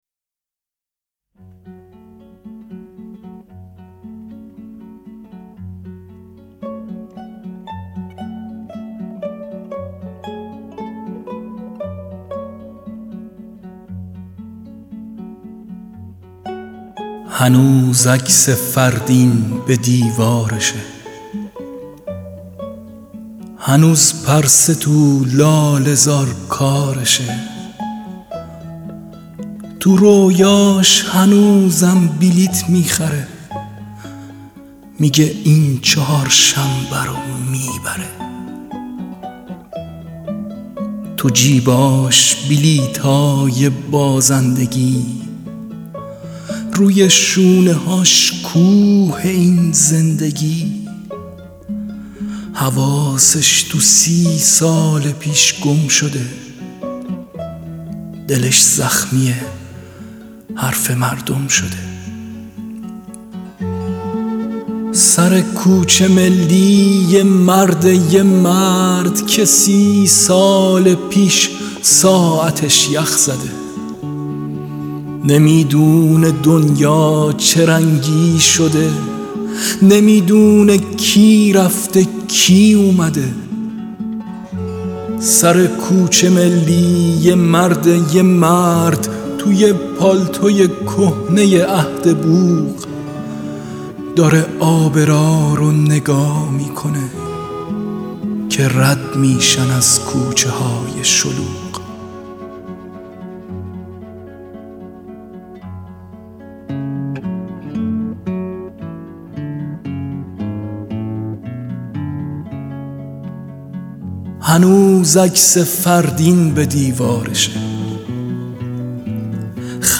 دانلود دکلمه کوچه ملی با صدای یغما گلروویی
گوینده :   [یغما گلرویی]